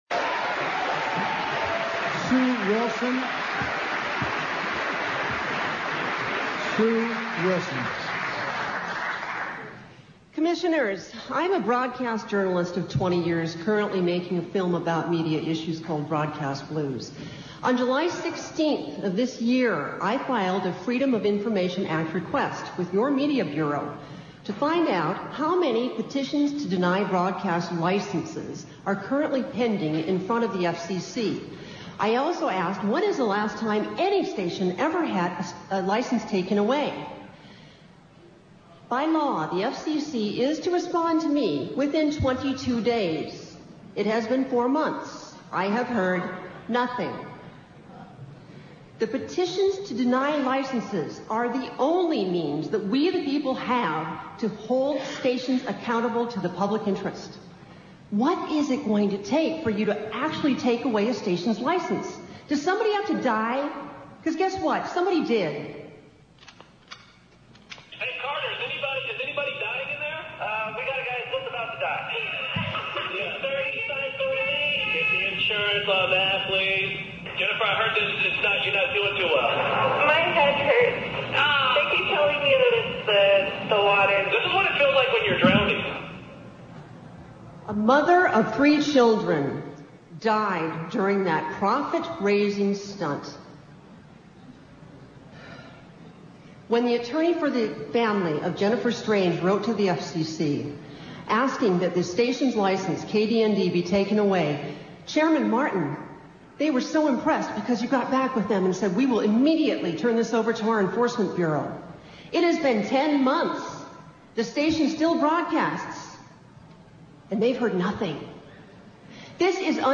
FCC holds public hearings on deregulation : Indybay